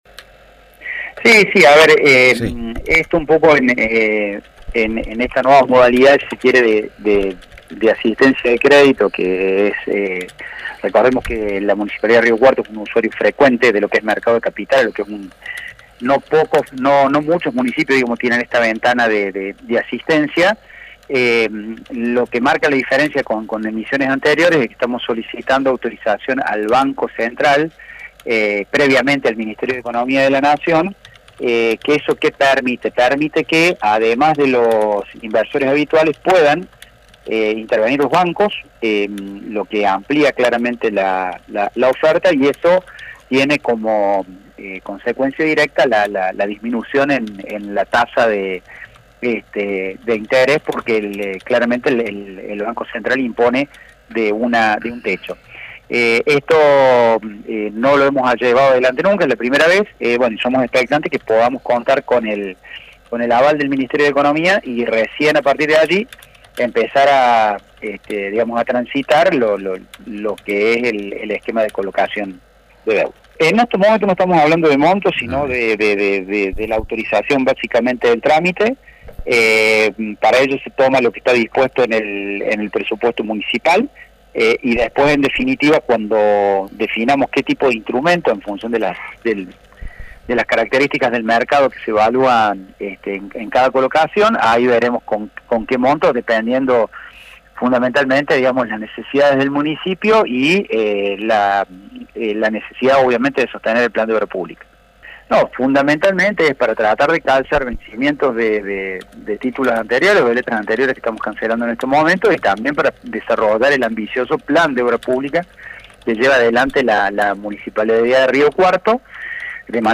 Pablo Antonetti, Secretario de Economía municipal, dijo que eso permite que inversores puedan intervenir en bancos.